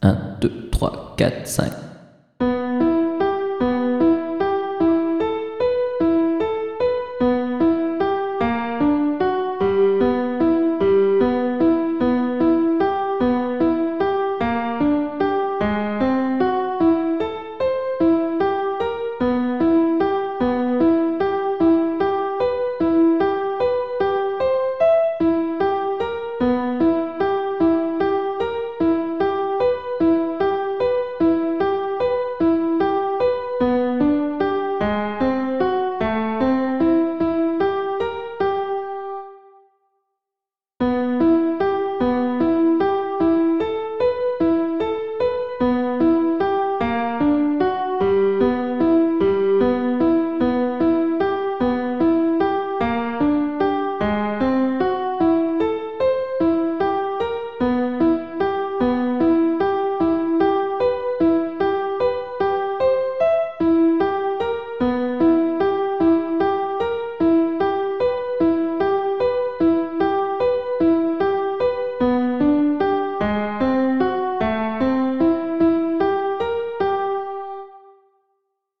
Accompagnement seul